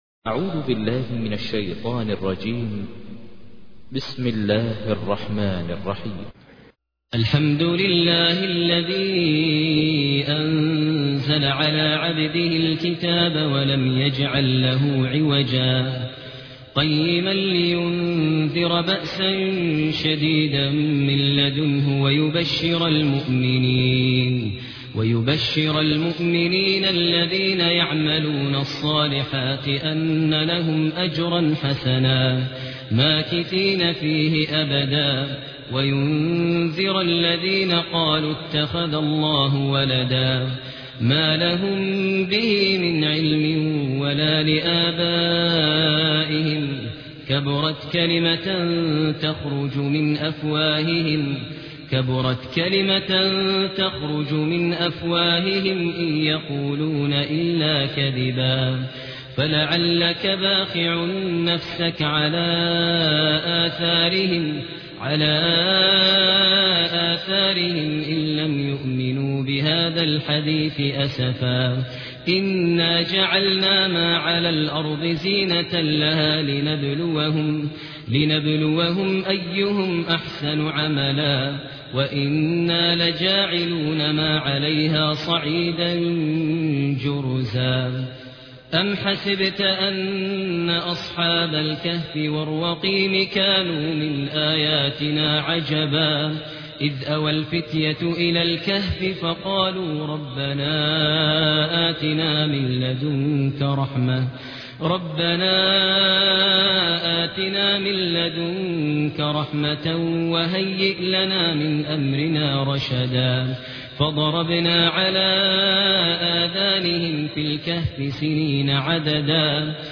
تحميل : 18. سورة الكهف / القارئ ماهر المعيقلي / القرآن الكريم / موقع يا حسين